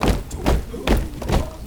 RUNINFEET2-L.wav